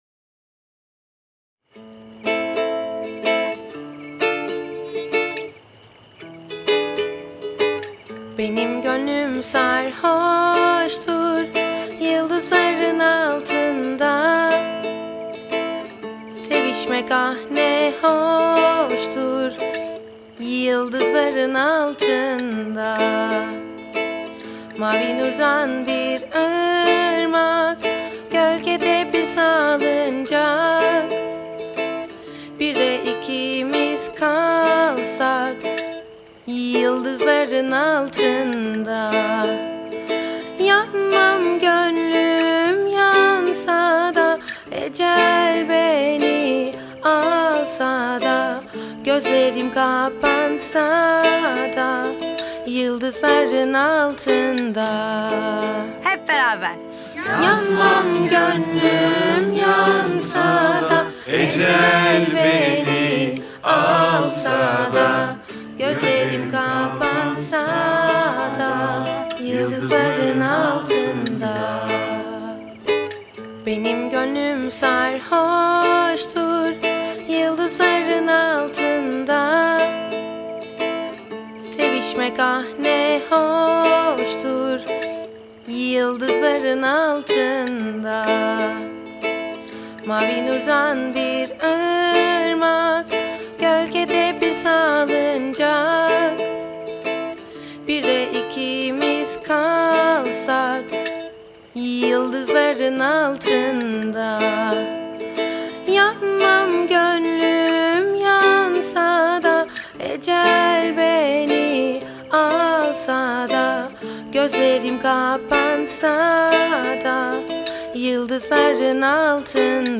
dizi müzikleri
mutlu huzurlu rahatlatıcı şarkı.